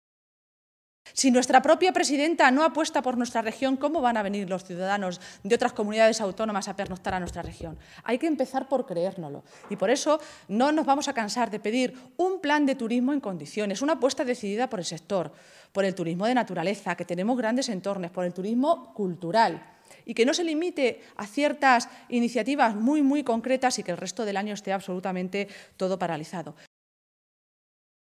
Cristina Maestre, vicesecretaria y portavoz del PSOE de Castilla-La Mancha
Cortes de audio de la rueda de prensa